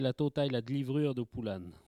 Langue Maraîchin
locutions vernaculaires